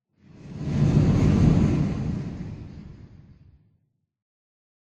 Sound / Minecraft / ambient / cave / cave11.ogg
cave11.ogg